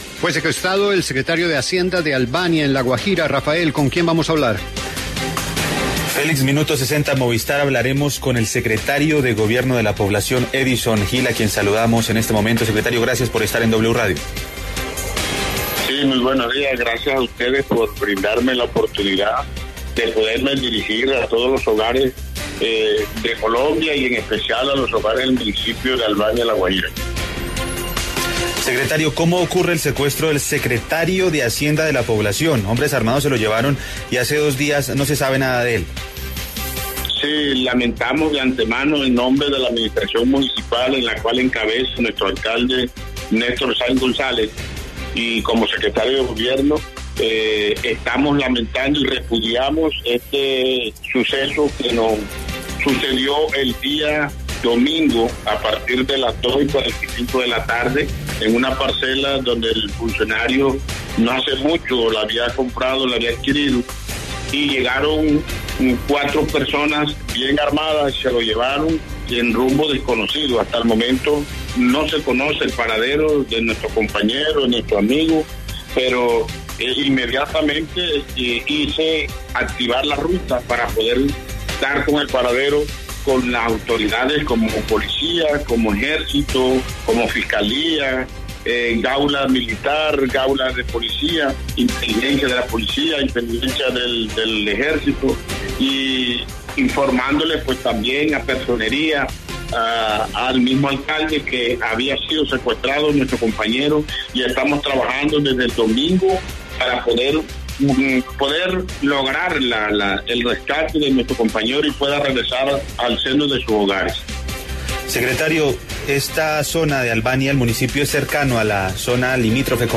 En diálogo con La W, el secretario de gobierno del municipio de Albania en La Guajira, Edison Gil, se refirió al secuestro del Secretario de Hacienda de la población, Roger Francisco Duarte, quien el pasado domingo fue interceptado por hombres armados con rumbo desconocido, cuando se encontraba en zona rural de la población.